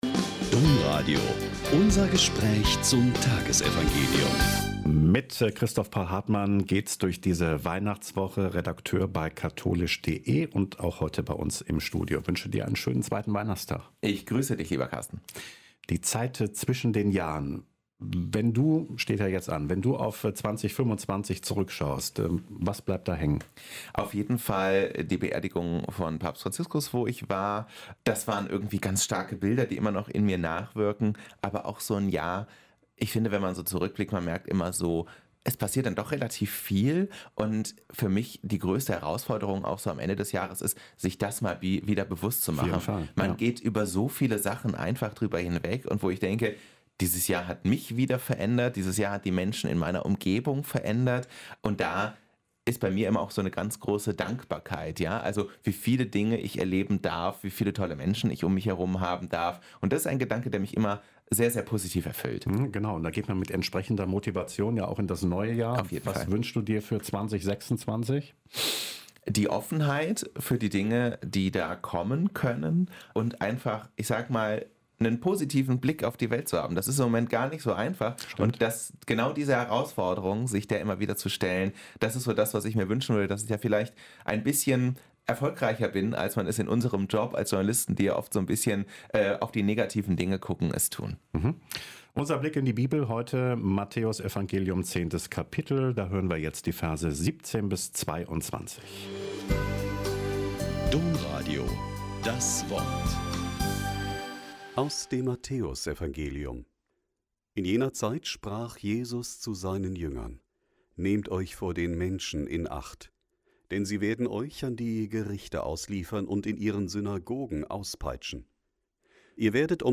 Mt 10,17-22 - Gespräch